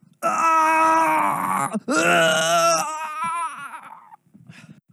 Agony Sound Effect.wav